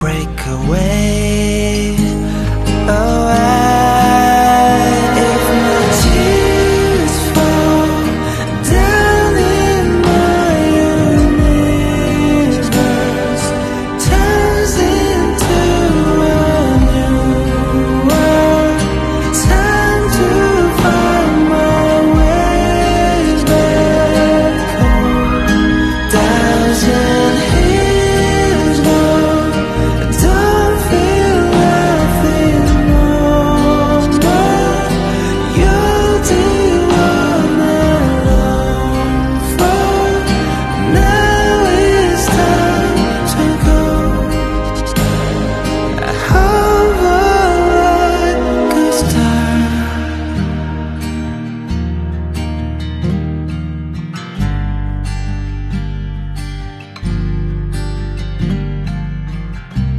Amsterdam, Ziggo Dome